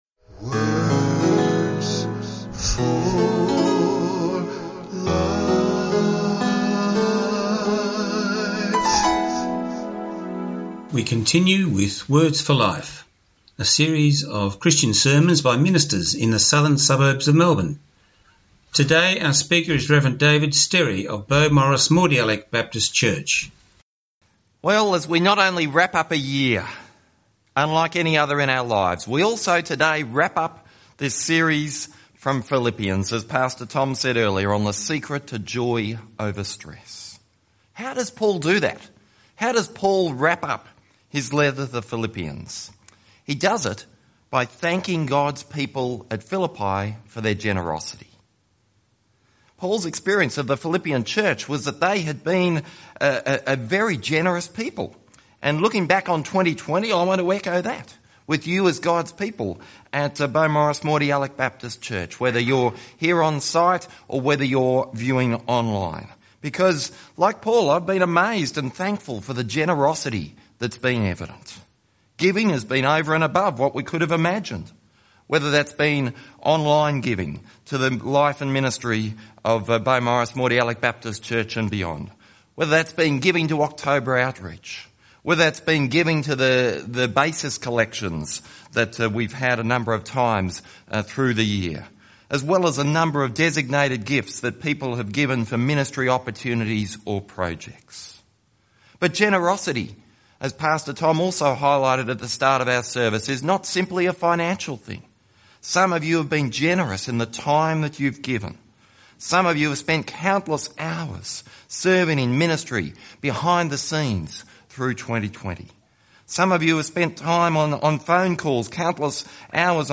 Talk time is 15 minutes.